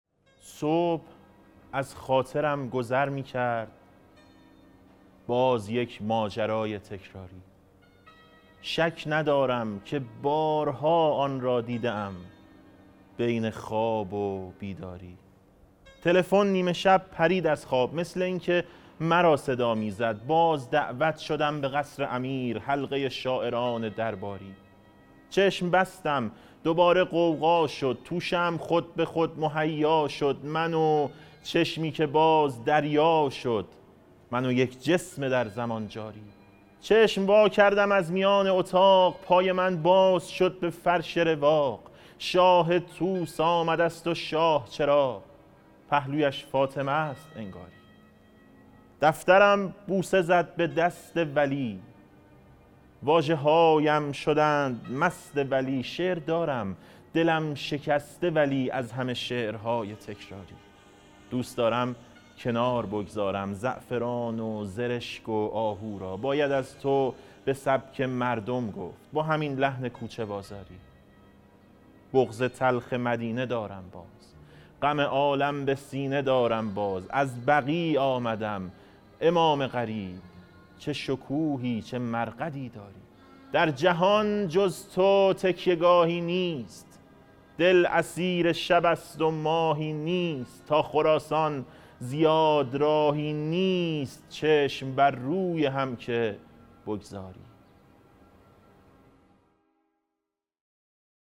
شعرخوانی | صبح از خاطرم گذر می‌کرد باز یک ماجرای تکراری
حرم حضرت معصومه (سلام الله علیها)_شهر مقدس قم | روایت هیأت فصل دوم